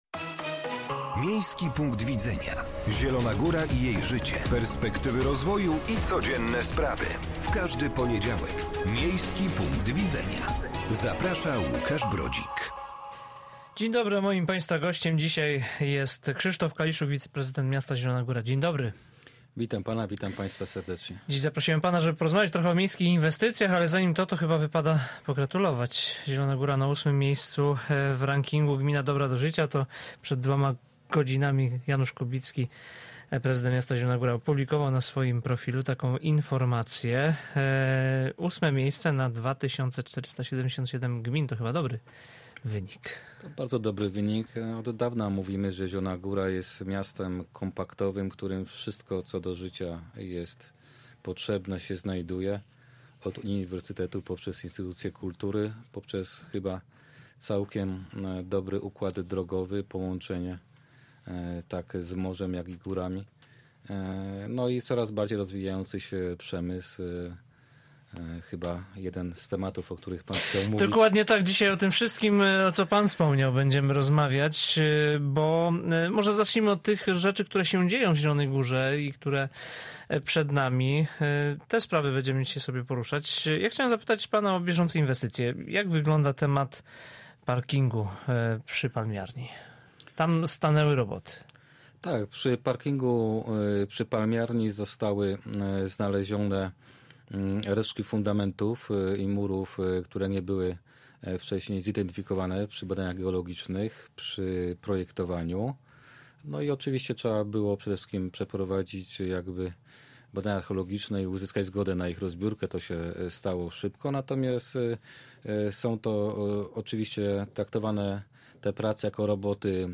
Z Krzysztofem Kaliszukiem, wiceprezydentem miasta Zielona Góra rozmawia